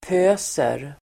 Uttal: [p'ö:ser]